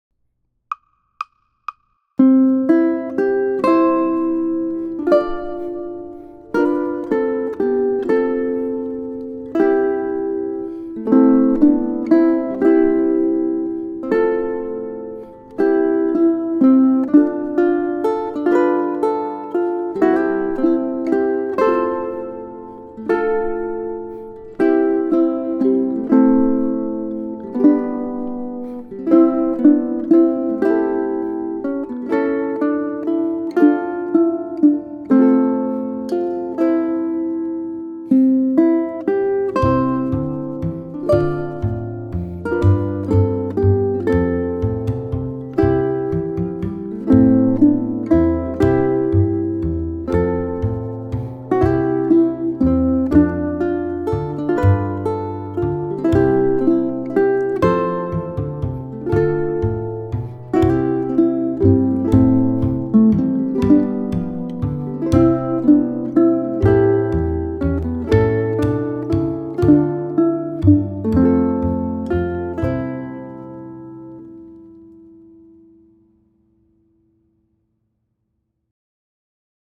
The melody is straightforward, slow-moving, and shouldn't present much difficulty.
The Simple strum 3/4 is recommended for this piece: strum downwards on the first beat of the measure with the flesh of your thumb. That is, strum once every three beats.
ʻukulele